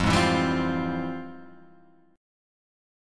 FmM9 chord